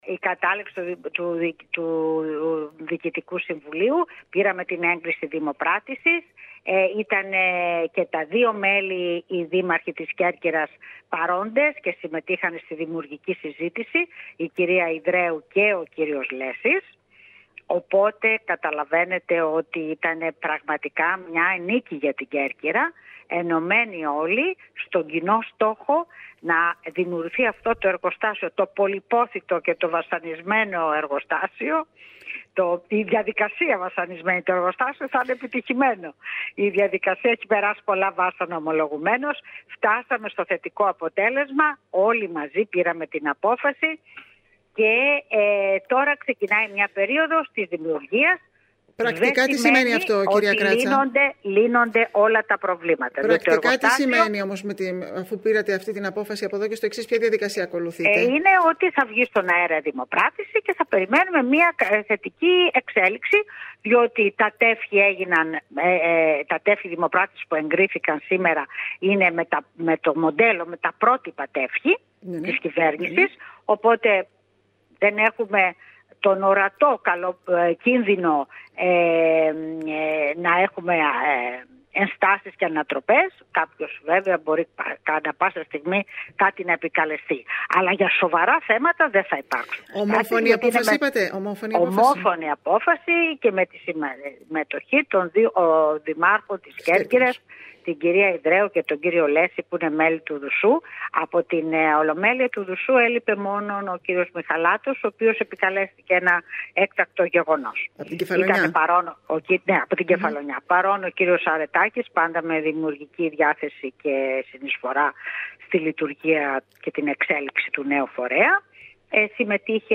Σύμφωνα με την Περιφερειάρχη Ρόδη Κράτσα, η οποία μίλησε σήμερα στην ΕΡΑ ΚΕΡΚΥΡΑΣ, η απόφαση ήταν ομόφωνη.